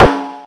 Snare 02.wav